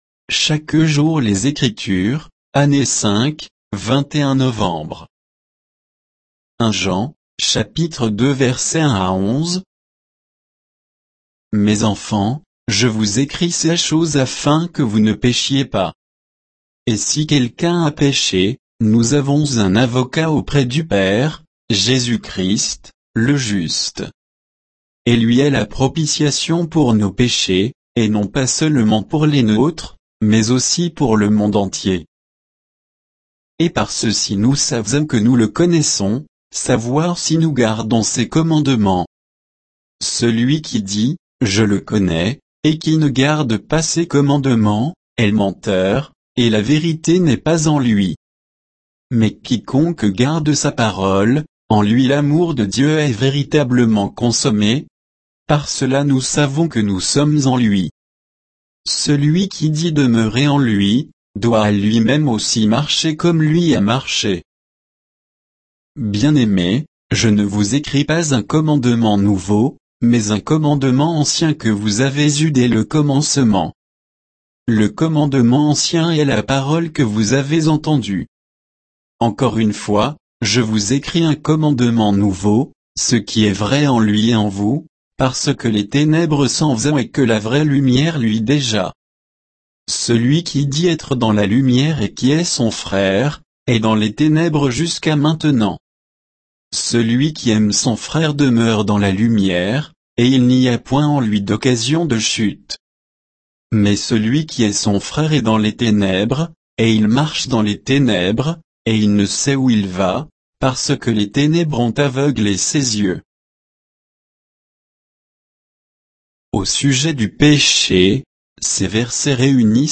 Méditation quoditienne de Chaque jour les Écritures sur 1 Jean 2